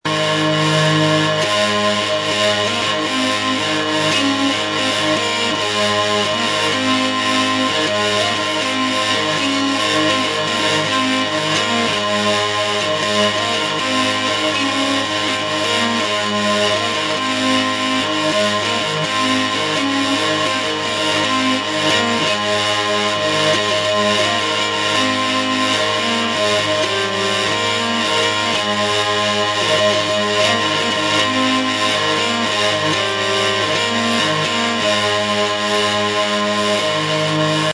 muestra de sonido [MP3 ] del organistrum o zanfona)
organistrum-son mp3.mp3